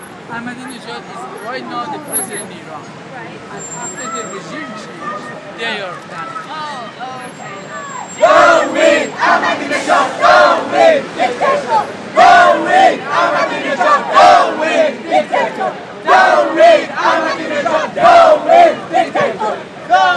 Demonstration while Iran's President spoke at UN. One protester said he watched Ahmadinejad torture his brother in prison in 1981.